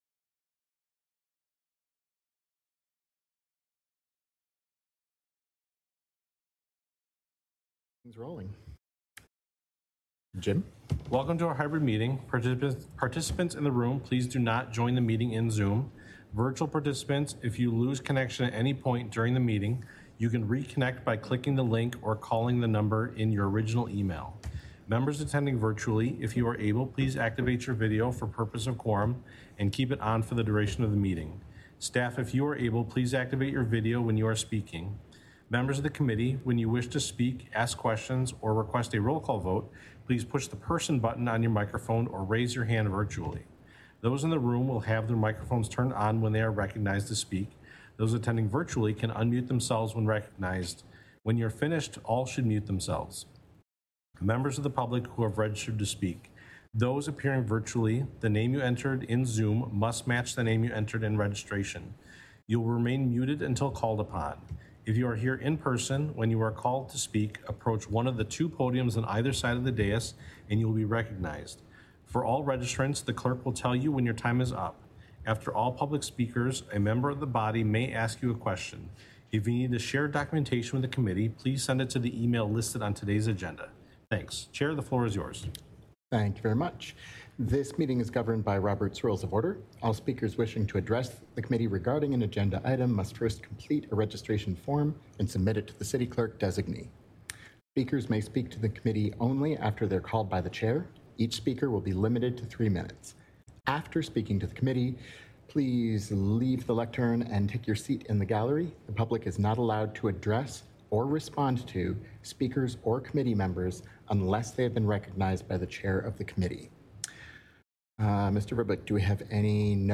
This is a podcast of City of Madison, Wisconsin Alcohol License Review Committee meetings.